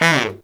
Index of /90_sSampleCDs/Best Service ProSamples vol.25 - Pop & Funk Brass [AKAI] 1CD/Partition C/BARITONE FX2